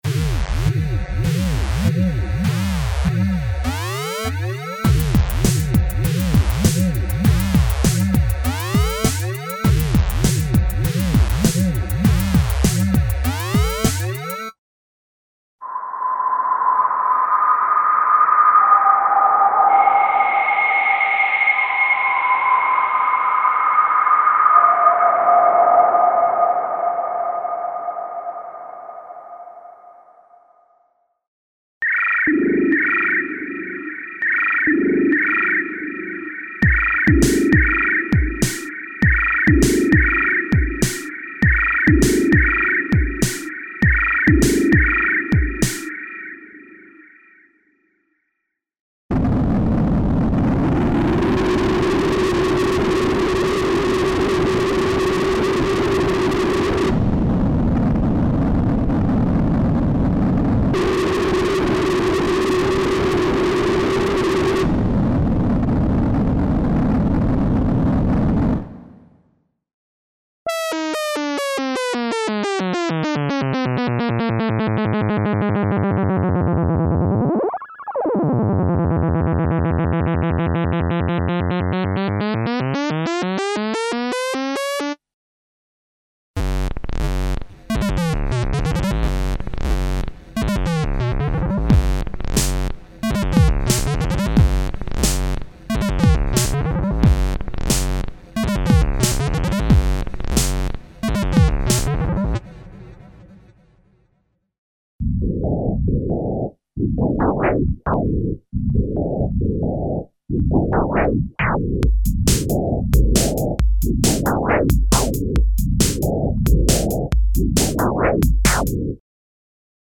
Emulations of vintage analog synthesizers (filtered and specially modulated noises and analog effects).
Info: All original K:Works sound programs use internal Kurzweil K2500 ROM samples exclusively, there are no external samples used.